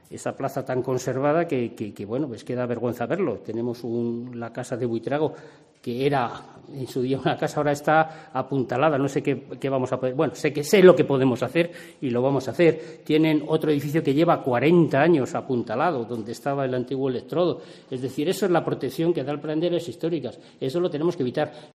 El comentario sobre la plaza de Santa Eulalia del concejal de Urbanismo y Patrimonio